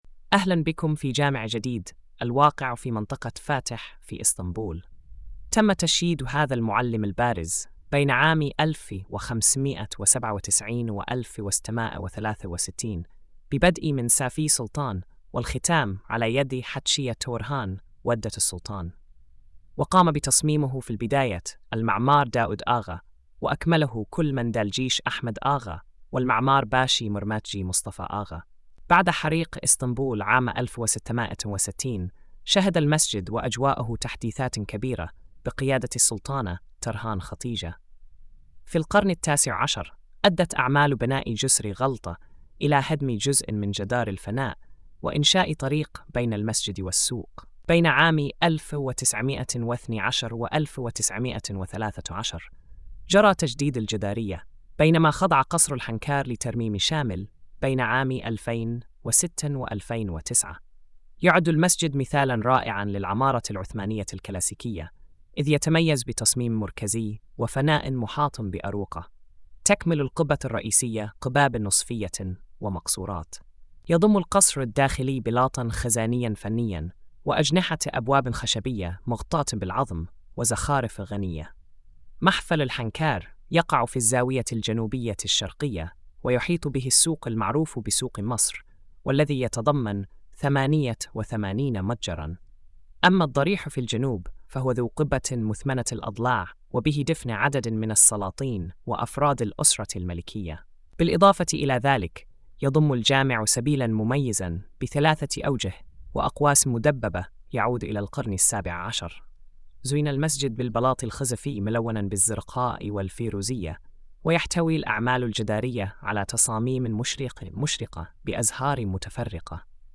السرد الصوتي